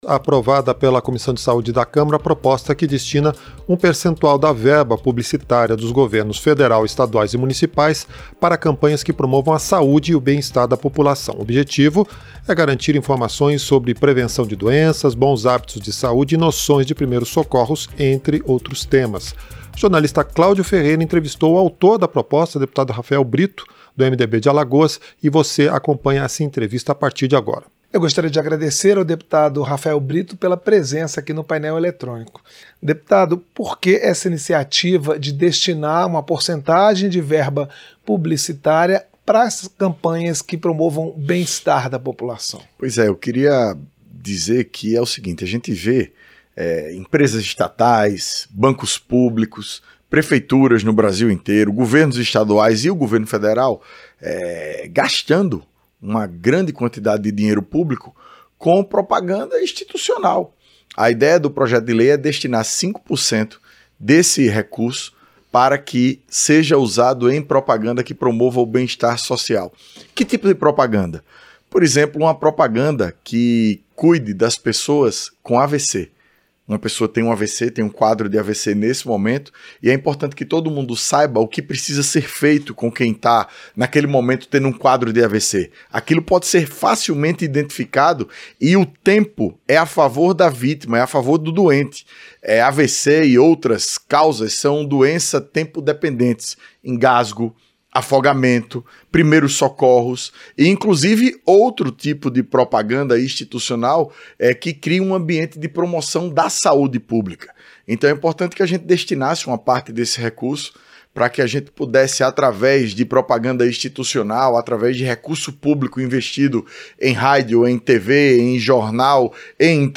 Entrevista - Dep. Rafael Brito (MDB-AL)